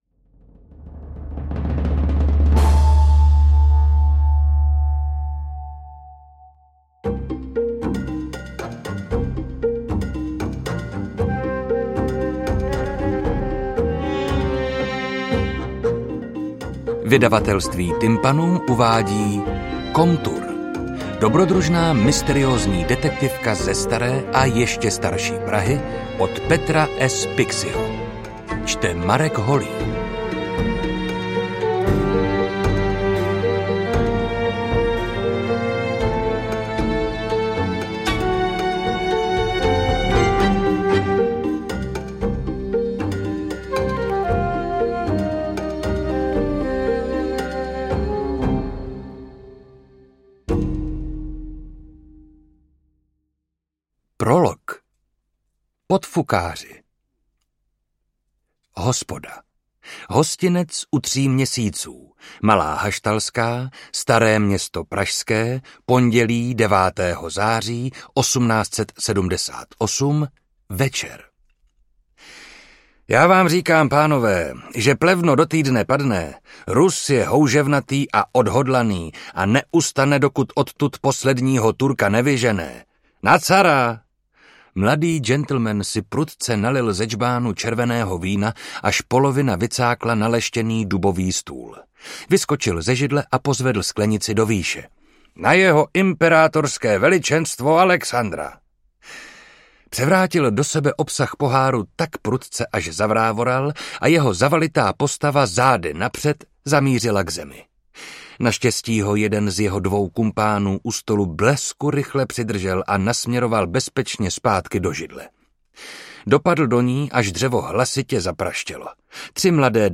Interpret:  Marek Holý
AudioKniha ke stažení, 54 x mp3, délka 16 hod. 23 min., velikost 900,4 MB, česky